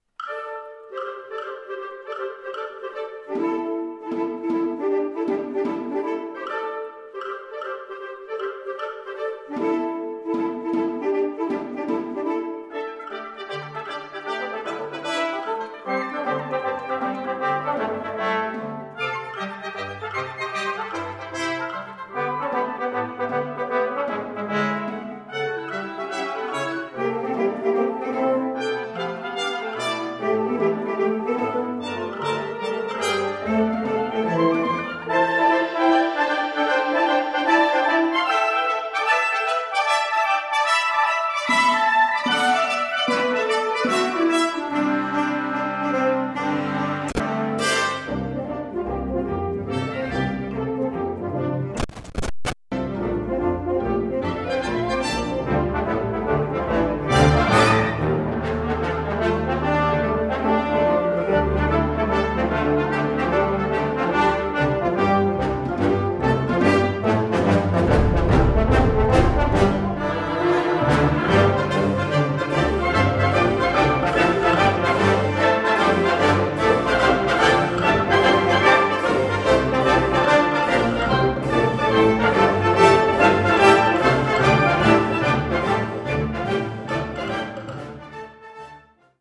Kategorie Blasorchester/HaFaBra
Allegro scherzando